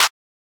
TS Clap_4.wav